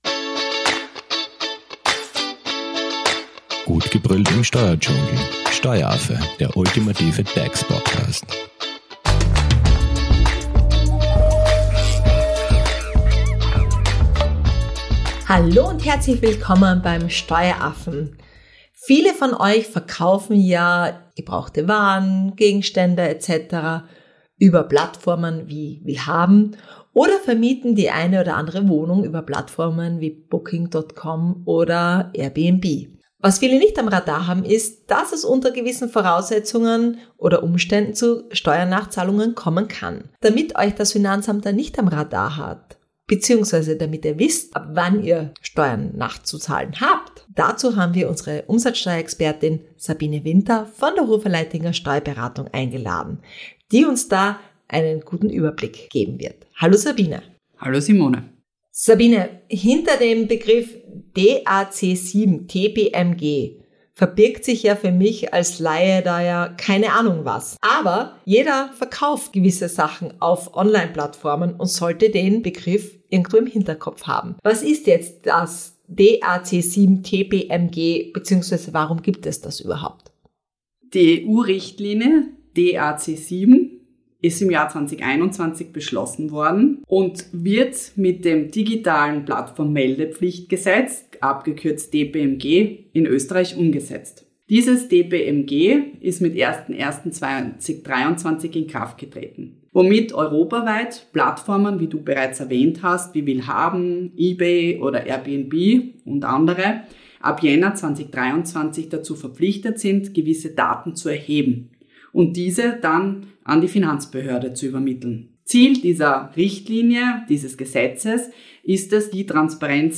Was ihr in dieser Folge zu hören bekommt … … sind Informationen, wann eine so genannte Meldepflicht nach DAC7 und DPMG besteht, welche Daten Plattformen an das Finanzamt melden dürfen und wo genau die Grenze zwischen privaten Verkäufen und gewerblicher Tätigkeit verläuft. Außerdem erfährt ihr, was zu tun ist, wenn eine Nachricht vom Finanzamt im Posteingang landet – und wie ihr unangenehme Überraschungen sicher vermeiden könnt. Zu Gast im Steueraffen-Studio ist Umsatzsteuerexpertin